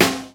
• 00's Natural Jazz Snare C Key 47.wav
Royality free snare sound tuned to the C note. Loudest frequency: 2446Hz
00s-natural-jazz-snare-c-key-47-Ueo.wav